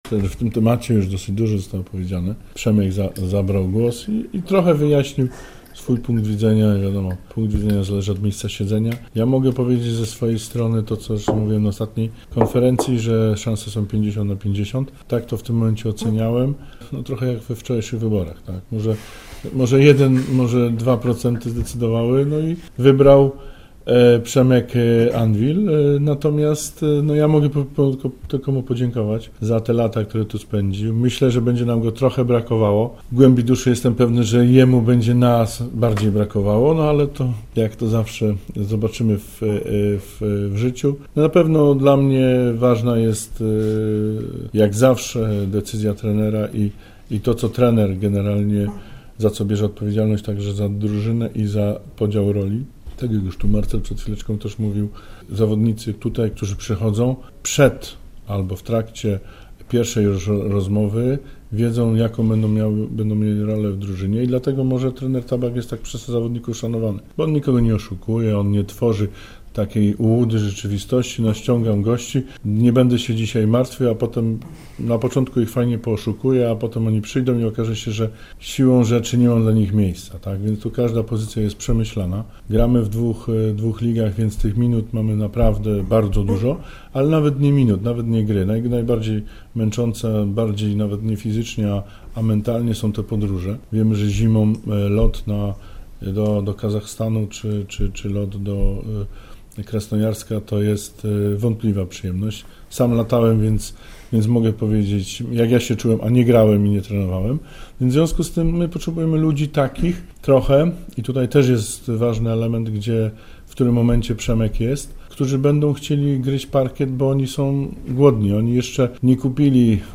Dziś klub potwierdził to na konferencji prasowej.